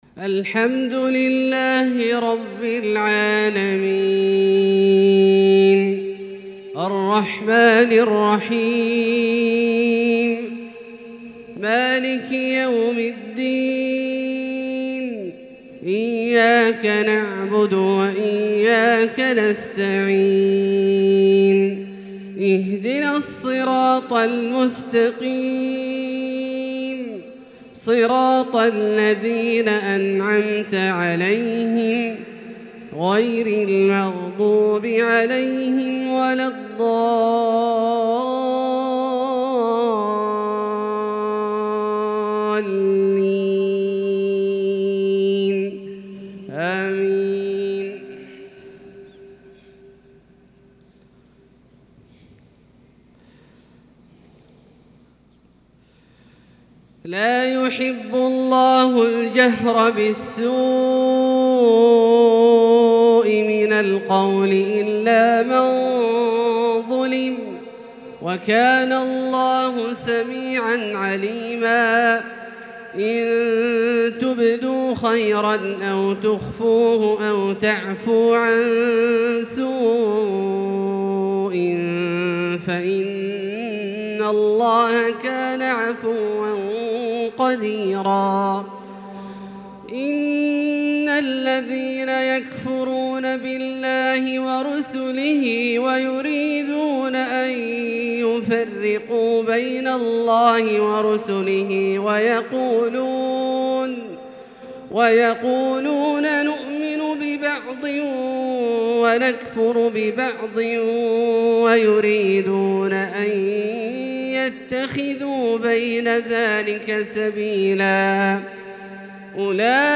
Fajr prayer from Surat An-Nisaa 8-8-2021 > H 1442 > Prayers - Abdullah Al-Juhani Recitations